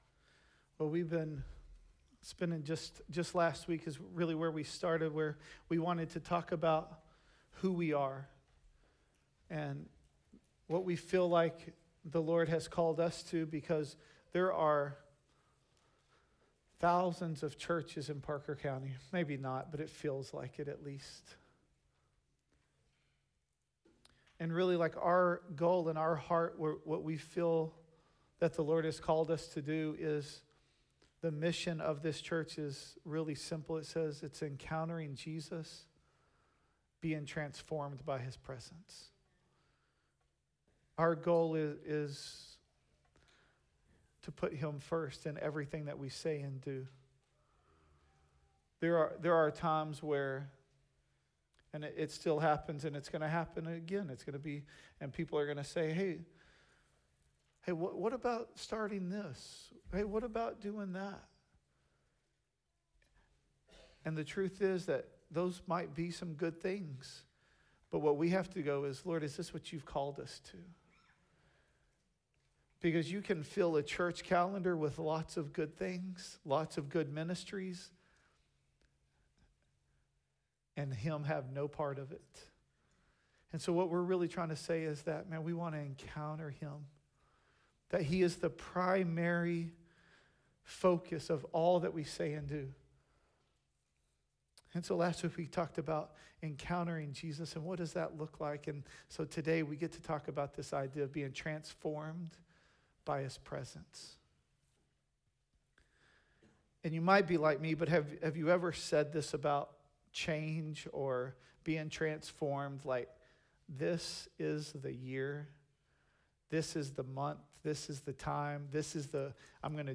The Gathering at Adell Audio Sermons Encountering Jesus.